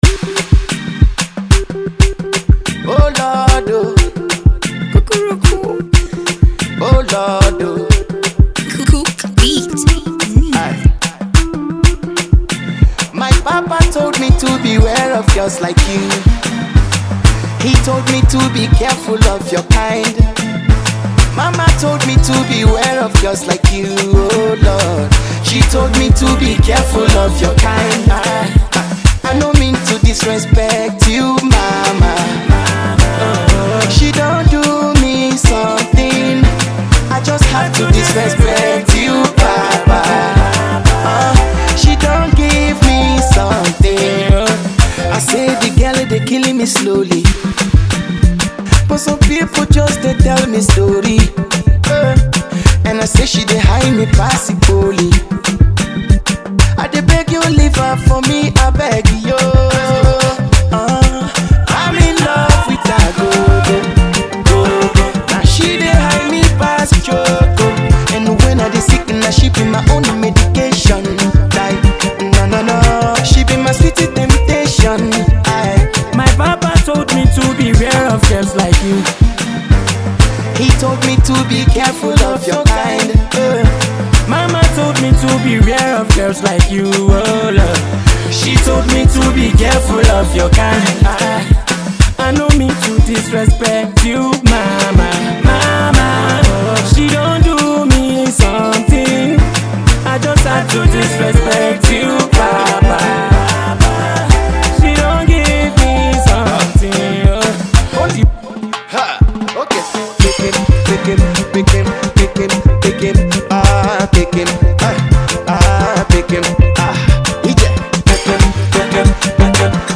danceable love song